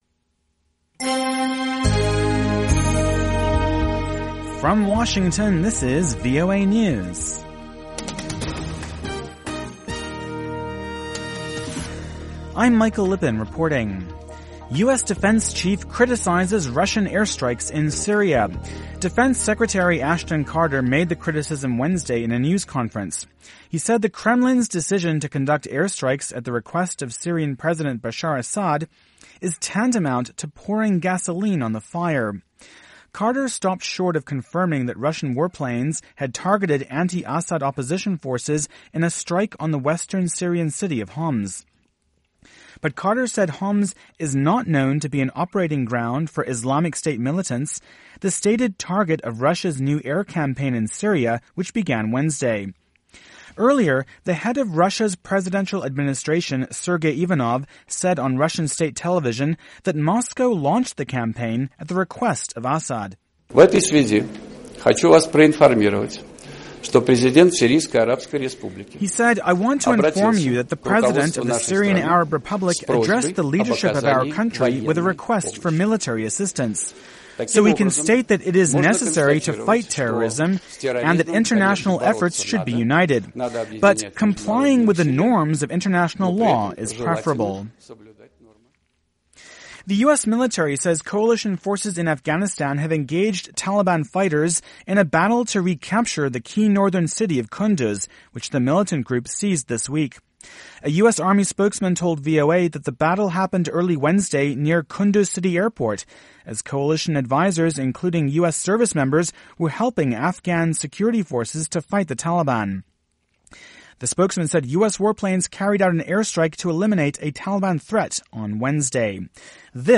VOA English Newscast: 2000 UTC September 30, 2015